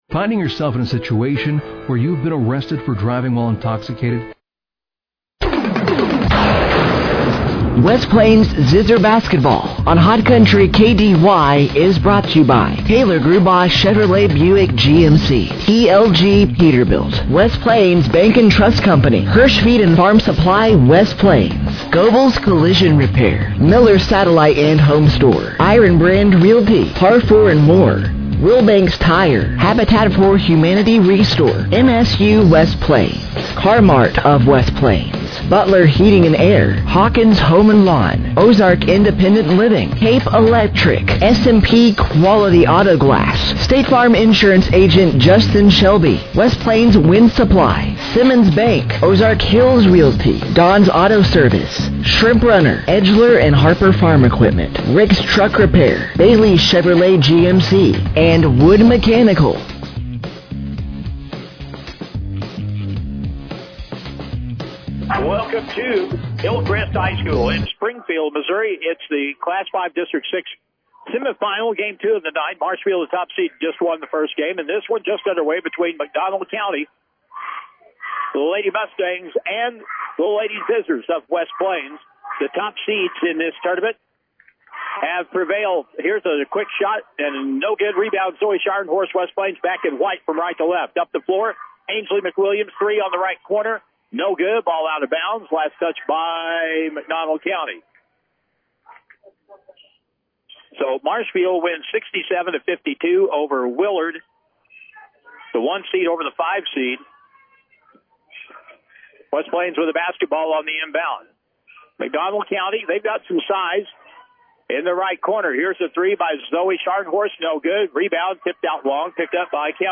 Game Audio Below: The West Plains Lady Zizzers played in their second round of districts at Hillcrest High School in Springfield on Wednesday night, March 4th, 2026 as they took on the Mcdonald County Lady Mustangs.
The West Plains Lady Zizzers played in their second round of districts at Hillcrest High School in Springfield on Wednesday night, March 4th, 2026 as they took on the Mcdonald County Lady Mustangs.